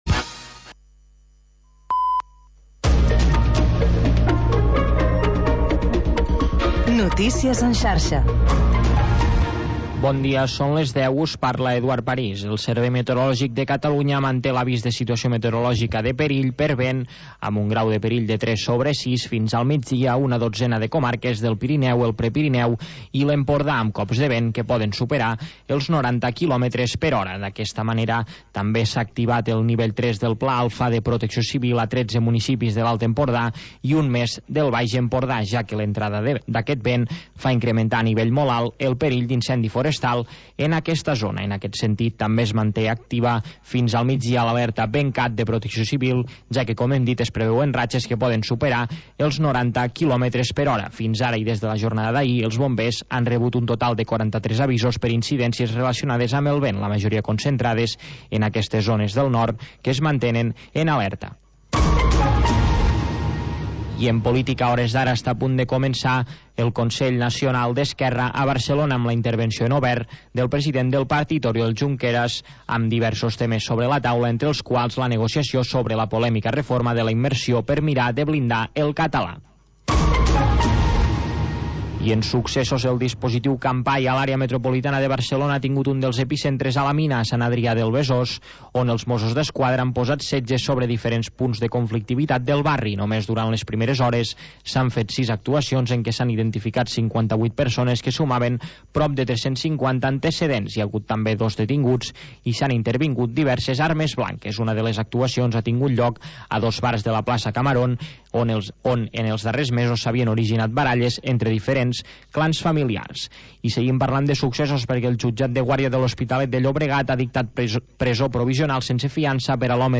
Programa sardanista, amb actualitat, compositors i agenda de ballades